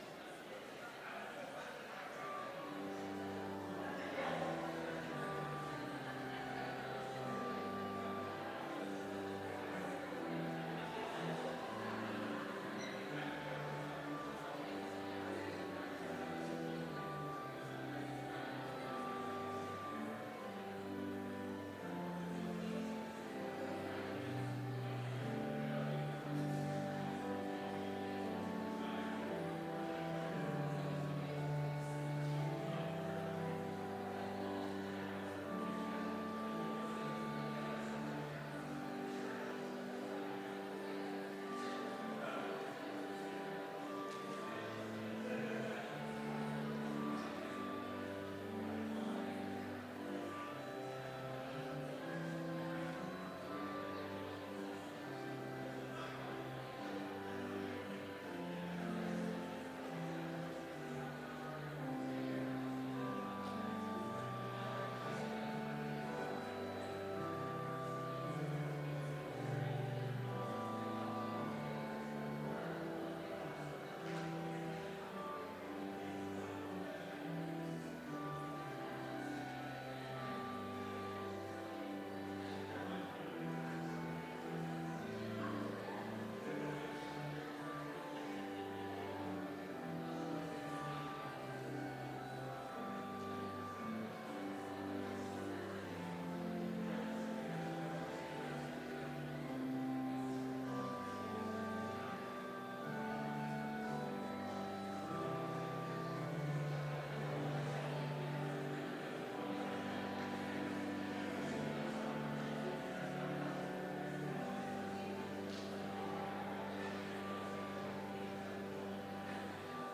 Complete service audio for Chapel - September 18, 2019